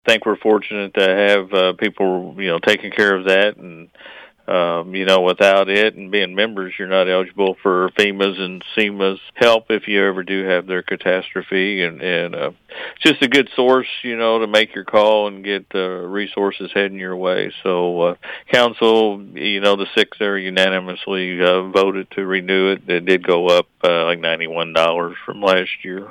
City Administrator Gene Griffith says being a member has plenty of benefits.